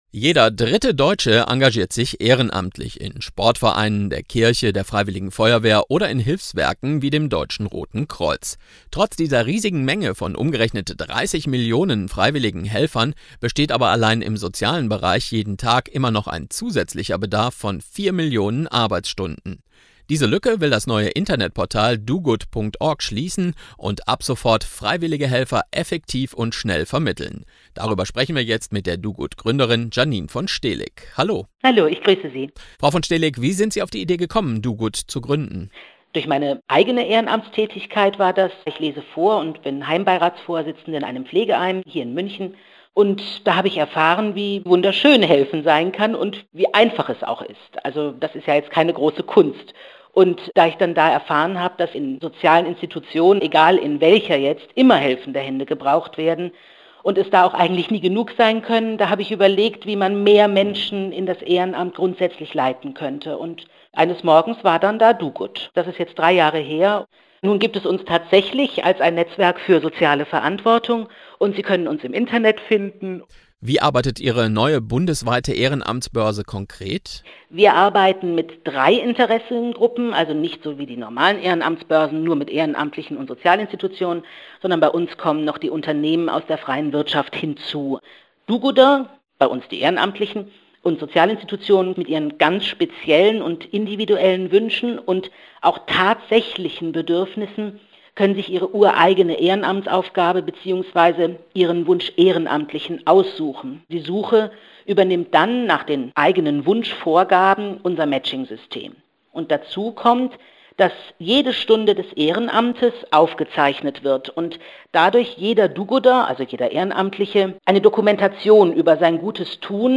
Interviews, O-Töne für die Presse
Radiointerview ohne Fragen des Moderators